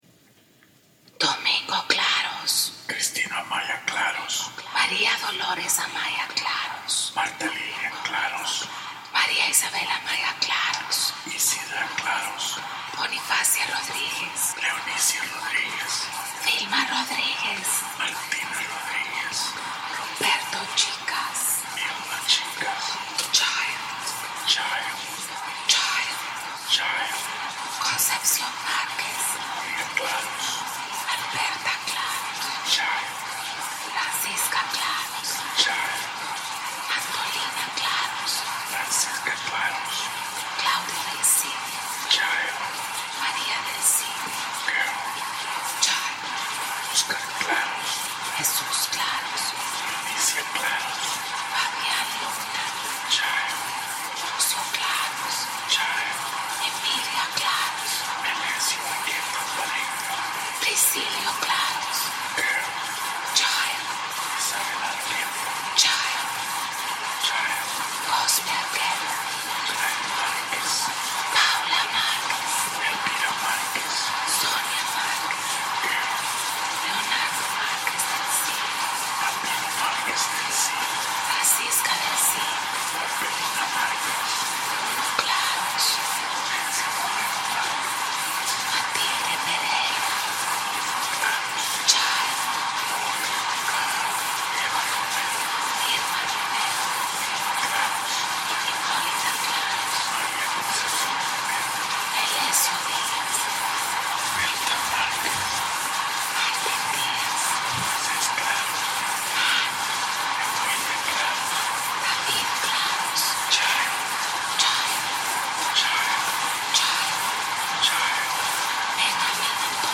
Presione el enlace para escuchar la instalación de sonido / Press on the link to listen to the sound installation.